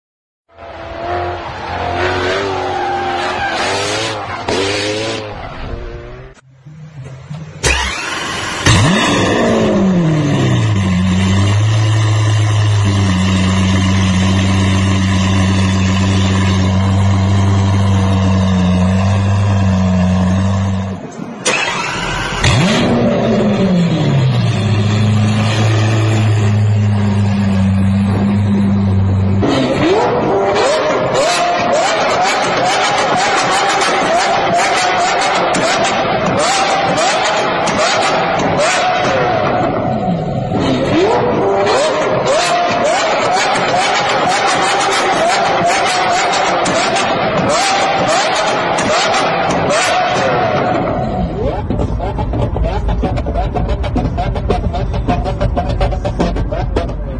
دانلود آهنگ صدای استارت ماشین از افکت صوتی حمل و نقل
دانلود صدای استارت ماشین از ساعد نیوز با لینک مستقیم و کیفیت بالا
جلوه های صوتی